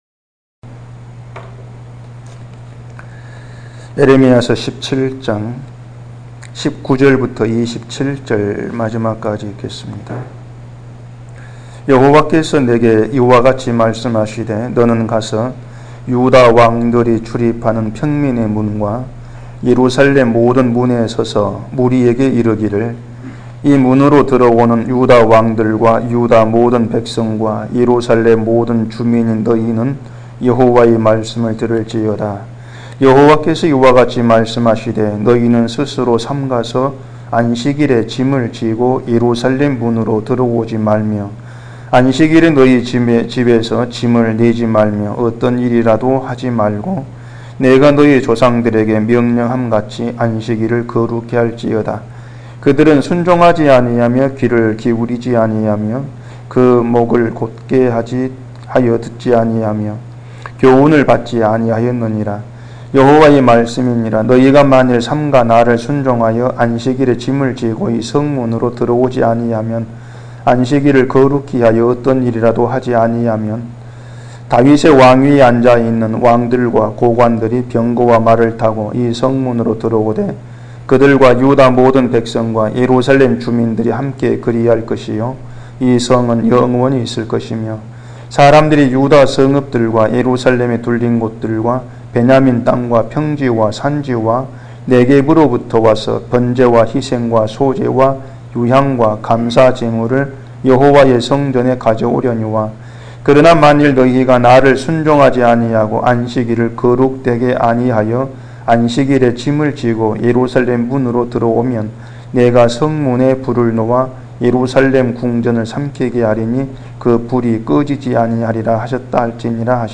<설교> 인간이 자기를 위해 살 때 나타나는 현상은 하나님이 말씀이 무시되는 것입니다.